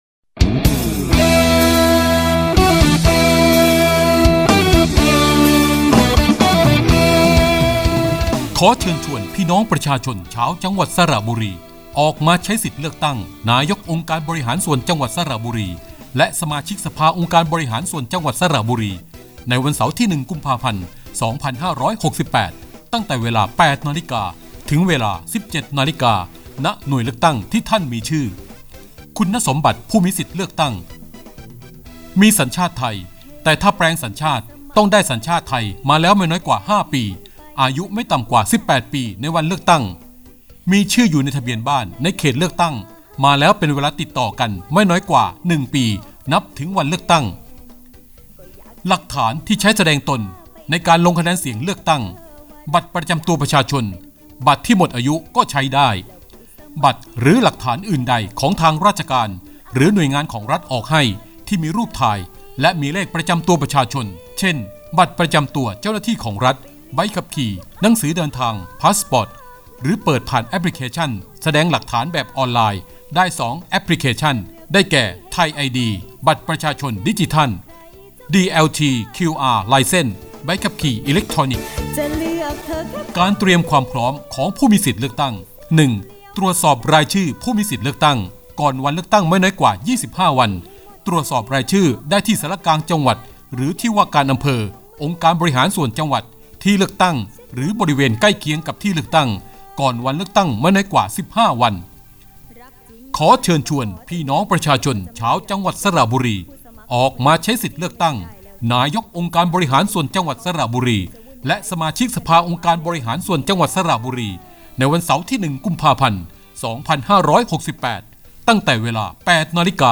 เสียงสปอตโฆษณา เชิญชวนออกไปใช้สิทธิ์เลือกตั้ง (ไฟล์ MP3)